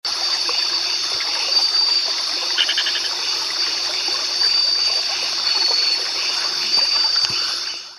Play call
Its call is quite distinctive, and can be described as a quiet rattle which is sometimes hard to discern above the noise of a fast-flowing stream.
pulchrana-sundabarat.mp3